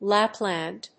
音節Lap・land 発音記号・読み方
/lˈæplænd(米国英語)/